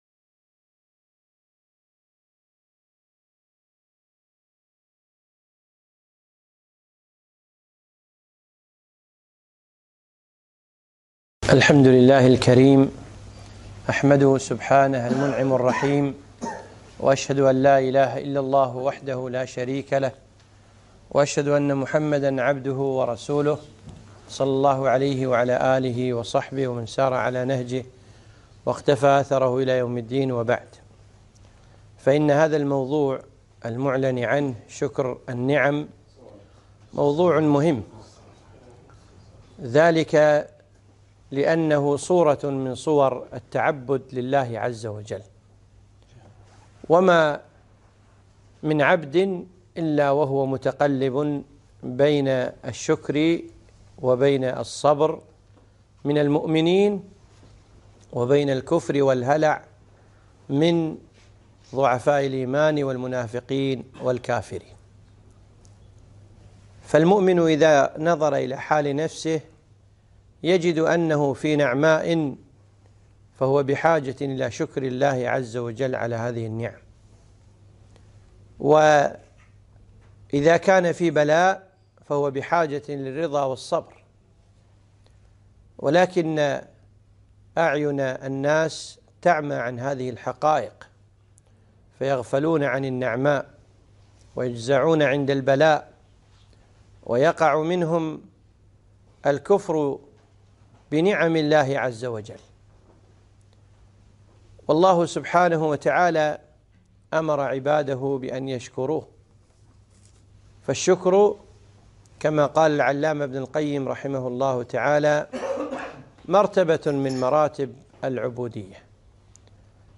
محاضرة - شكر النعم